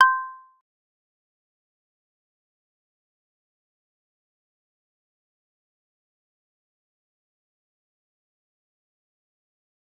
G_Kalimba-C7-mf.wav